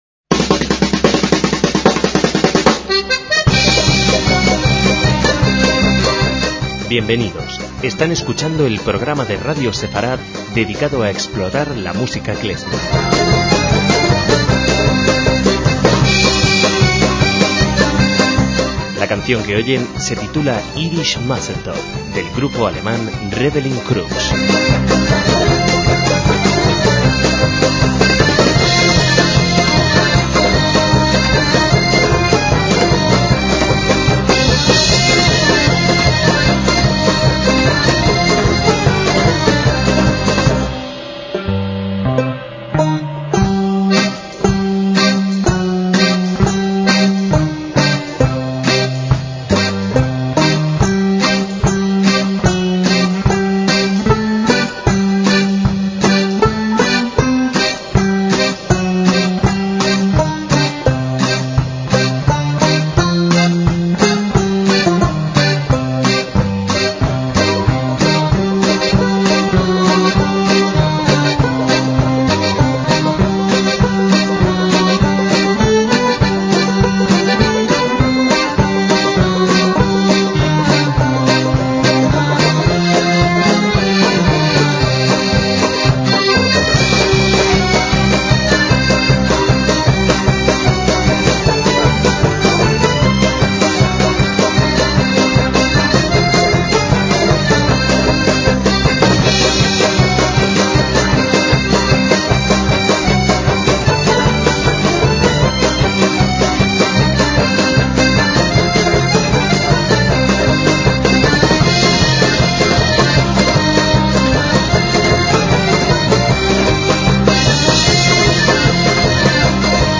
MÚSICA KLEZMER
es una banda alemana compuesta de 11 músicos